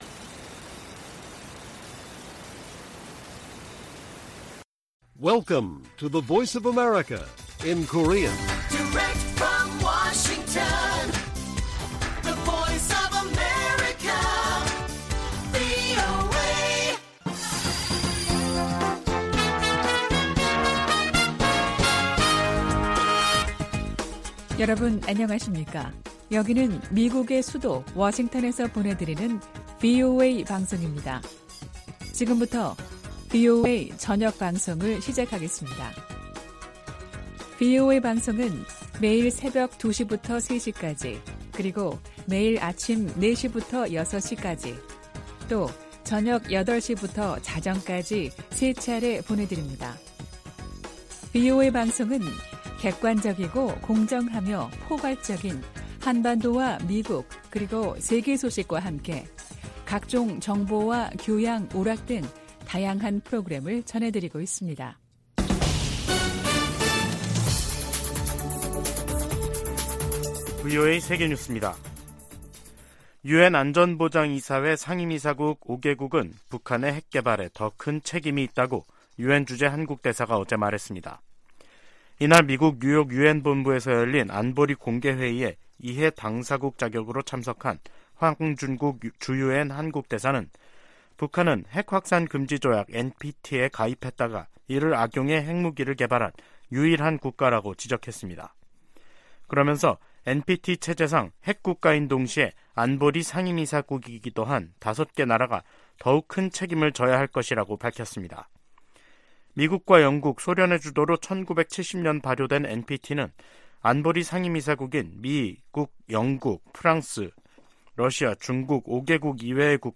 VOA 한국어 간판 뉴스 프로그램 '뉴스 투데이', 2023년 4월 18일 1부 방송입니다. 백악관은 정보당국의 도·감청 문건과 관련해 신속한 조치를 취하고 있으며, 파트너 국가들과의 신뢰는 훼손되지 않았다고 평가했습니다. 미 국방부는 도·감청 문제와 관련, 특히 한국과는 매우 좋은 관계를 유지하고 있다고 강조했습니다. 유엔 안전보장이사회가 북한의 신형 대륙간탄도미사일(ICBM) 관련 공개회의를 열고 반복 도발을 강하게 규탄했습니다.